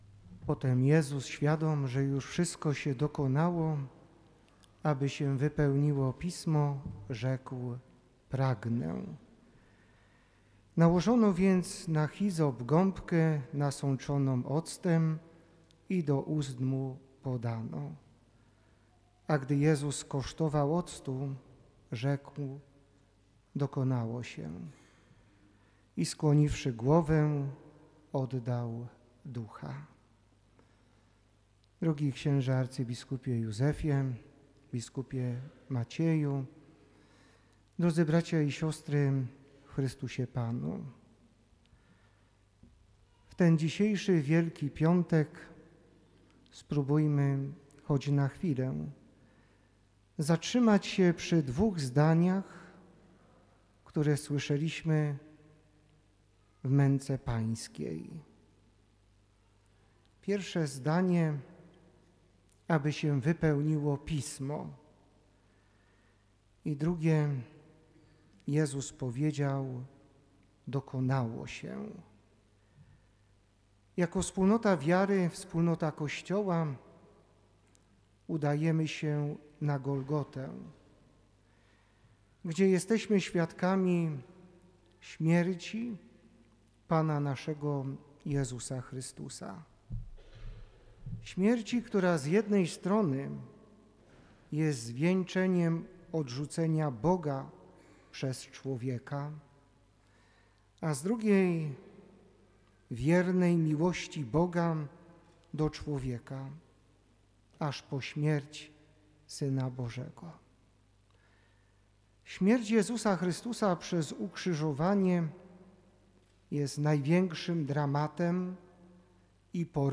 Liturgia Męki Pańskiej ( Homilia) - Radio Rodzina
W Wielki Piątek o godzinie 18:30 w Katedrze Wrocławskiej sprawowana była Liturgia Męki Pańskiej.
Transmitowanej przez Radio Rodzina liturgii przewodniczył o. bp Jacek Kiciński.